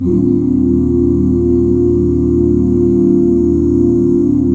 EBMAJ7 OOO-R.wav